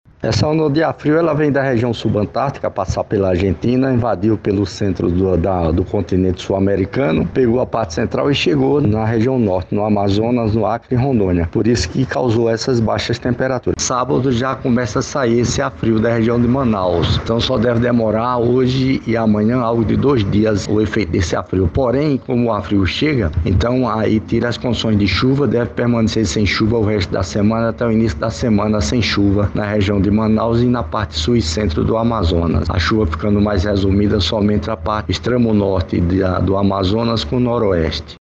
Segundo o meteorologista e consultor climático